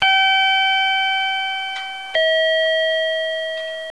鉄軌道駅の改札
oto_guidechaim.wav